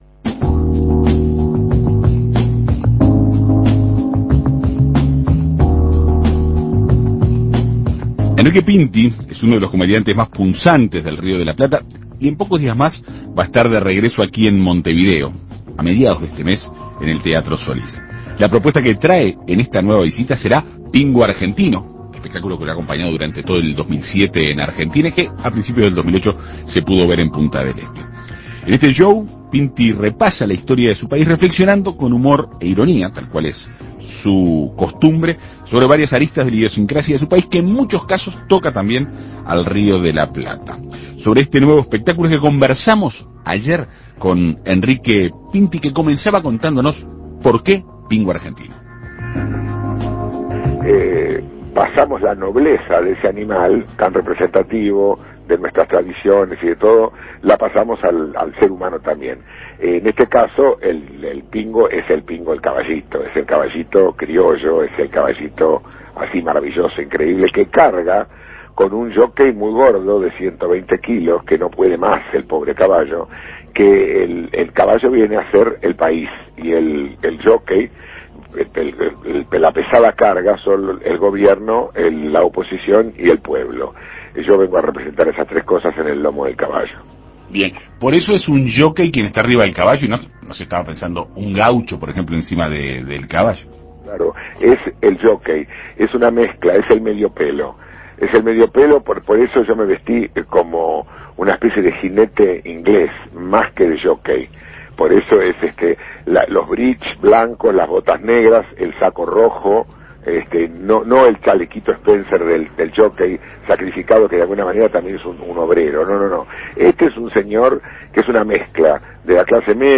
En abril el comediante argentino Enrique Pinti llegará al Teatro Solís, en Montevideo, con el espectáculo "Pingo argentino". Para saber más sobre este show, En Perspectiva Segunda Mañana dialogó este martes con Pinti.